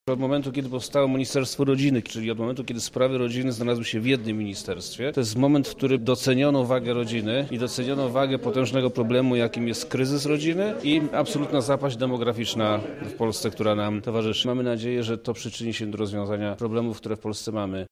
Wczoraj w Lubelskim Urzędzie Wojewódzkim odbyła się konferencja prasowa w związku z obchodami międzynarodowego dnia rodzin.
O wnioskach z działalności rządu na rzecz rodzin – Przemysław Czarnek, wojewoda lubelski